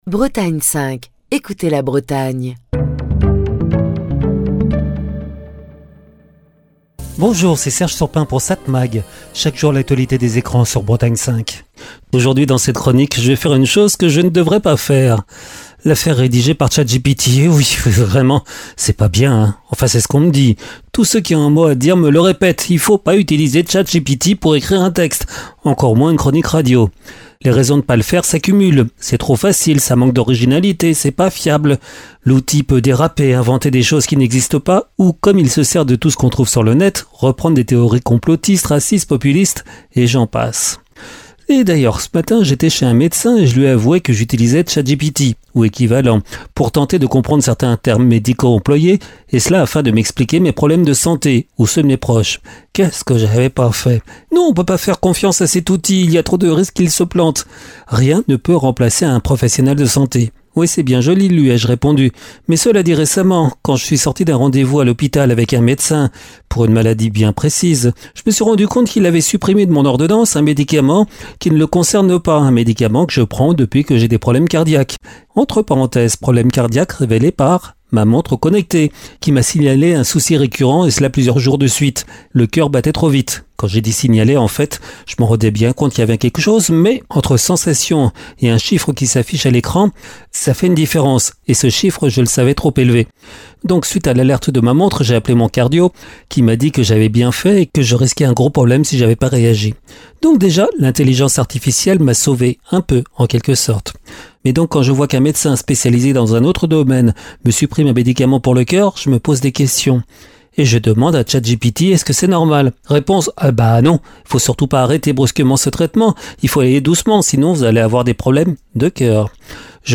Chronique du 8 octobre 2025. Et si ChatGPT n’était pas un ennemi, mais un allié ?